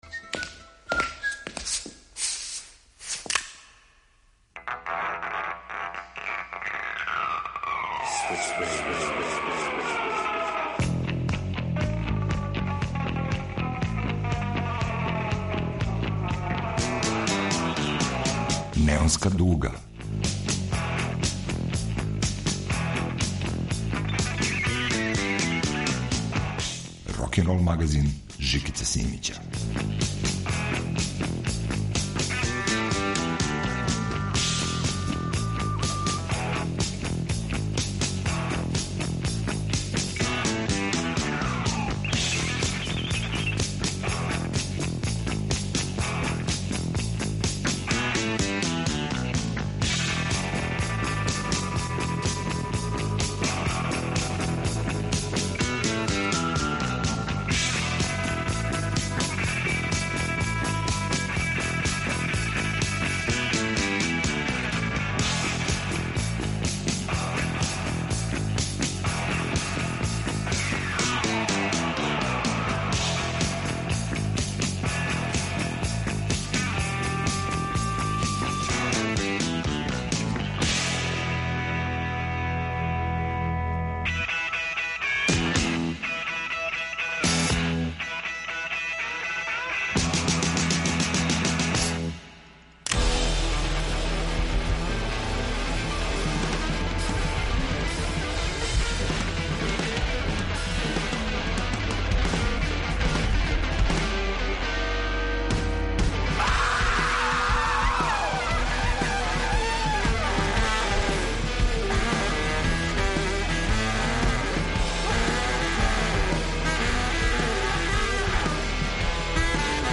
Рокенрол као музички скор за живот на дивљој страни.